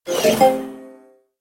Звуки чекпоинта
На этой странице собраны звуки чекпоинтов — от четких электронных сигналов до игровых оповещений.